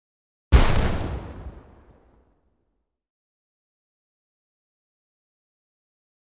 Large Single Cannon Fire; Single Cannon Fire. Medium Sized, Sharp Blast With Little Echo. Medium Perspective.